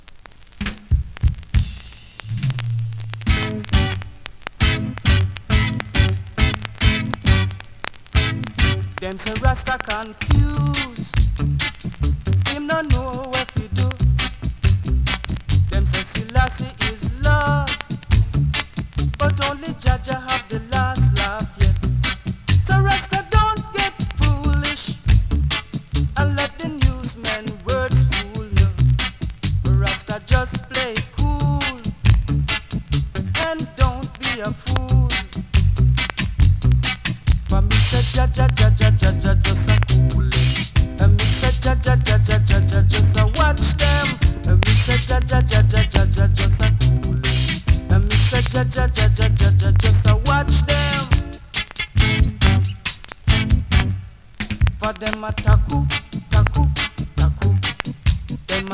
SKA〜REGGAE
スリキズ、ノイズ比較的少なめで